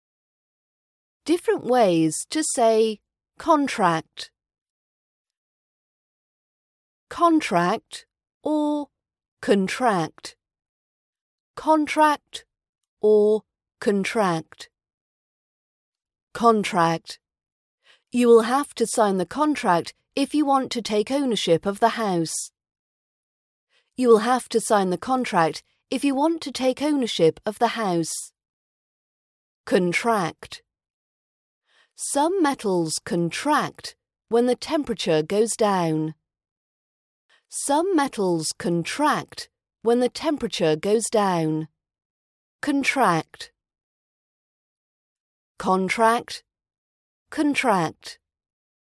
Different ways to say Contract - RP British Accent pronunciation practice